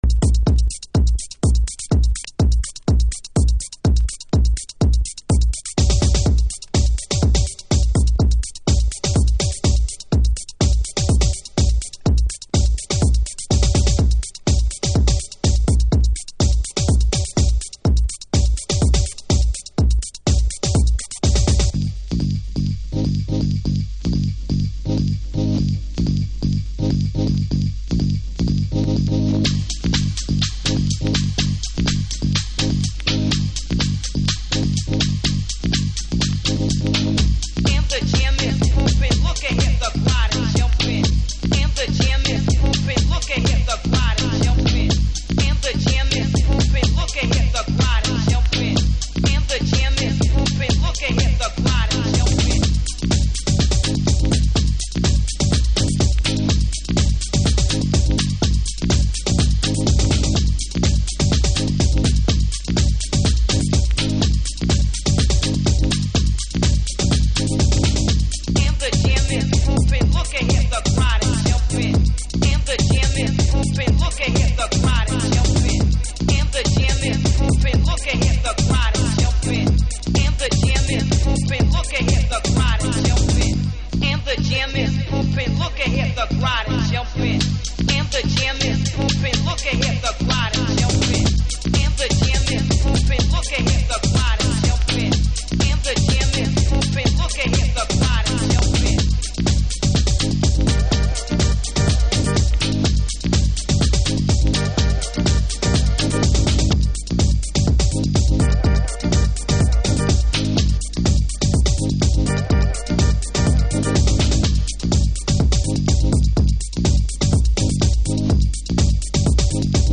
Early House / 90's Techno
この再発シリーズのありがたいトコロは原曲を崩さずに音を太くしてくれています。